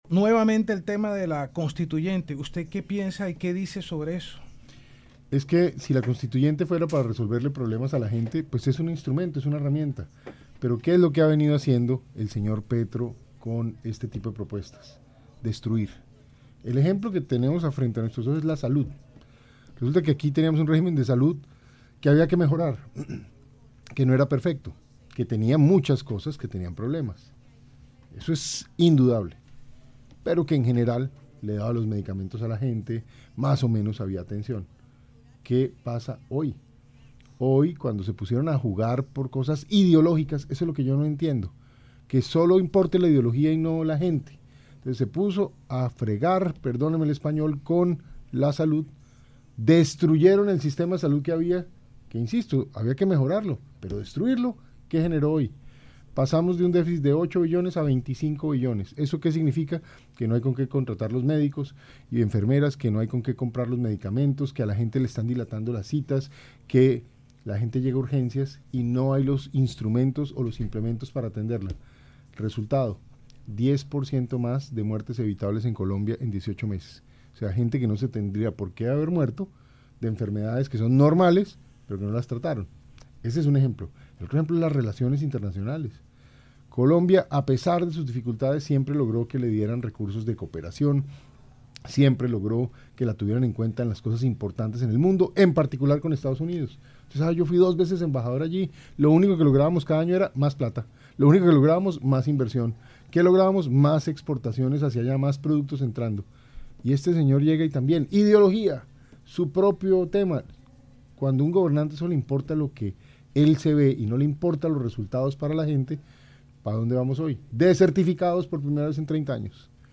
Entrevista Pinzón